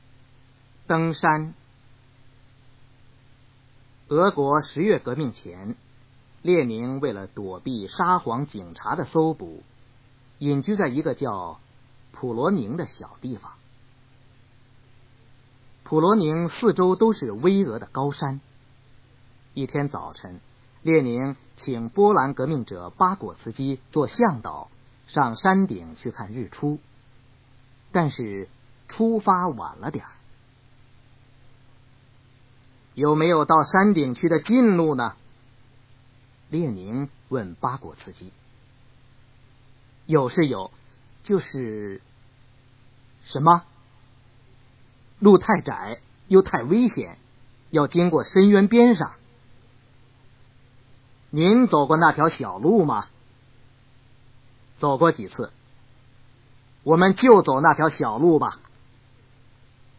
三年级语文下册课文朗读 登山 1（教科版）_21世纪教育网-二一教育